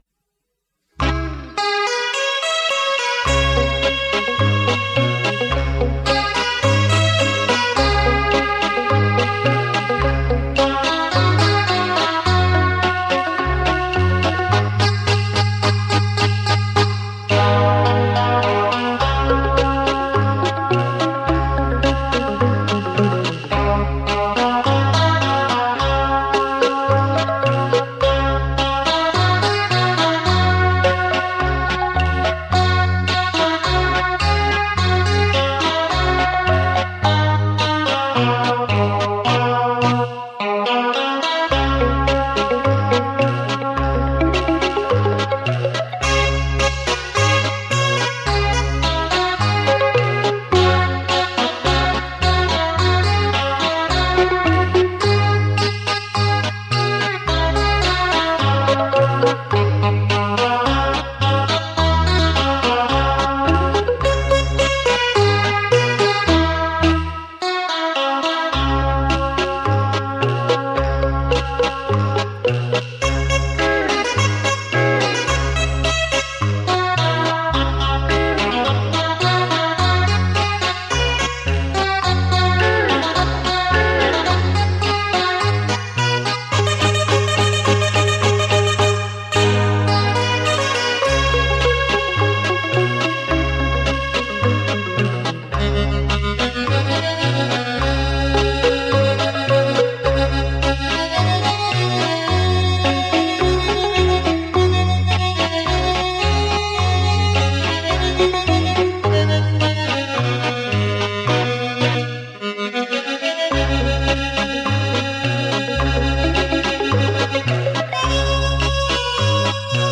特殊效果+600百万电脑磁碟琴主奏
伦巴舞曲